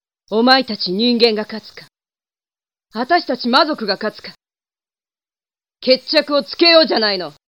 제가 전문가가 아니라서 추출한 음성파일 음질이 좀 미흡하네요.